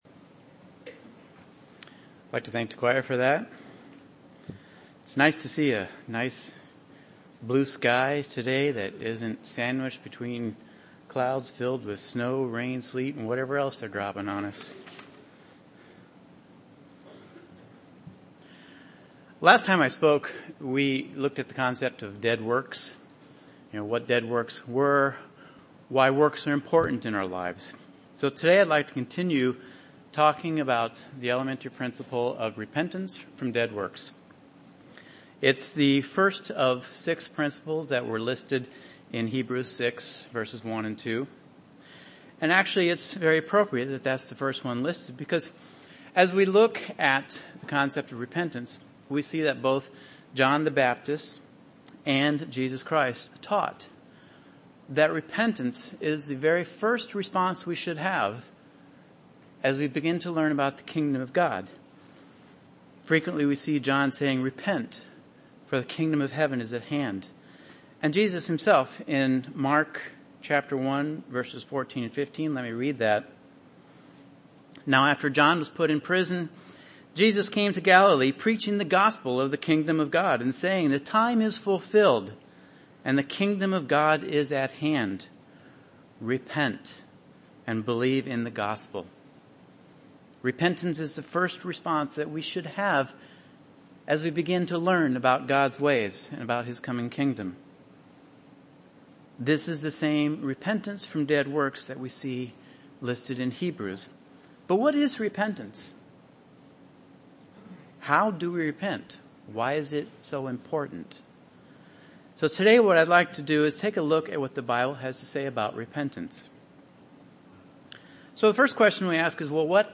Given in Chicago, IL
UCG Sermon Repentance works Studying the bible?